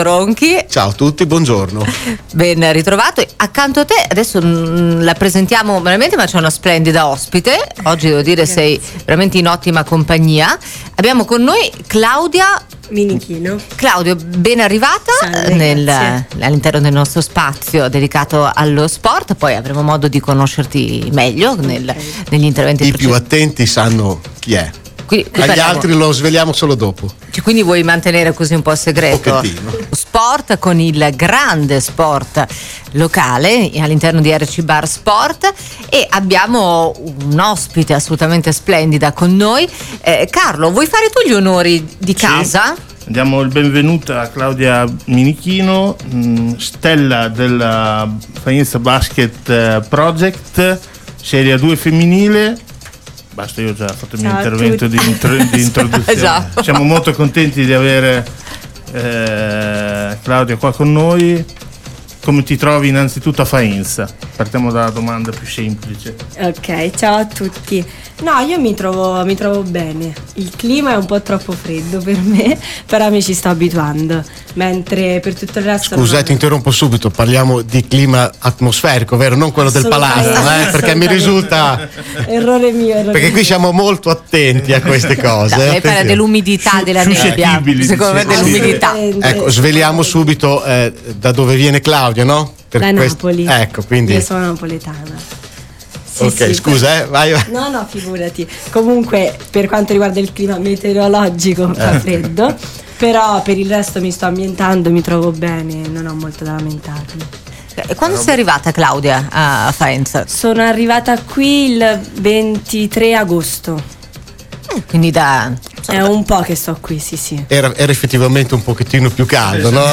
Ed ecco la traccia con l’intervista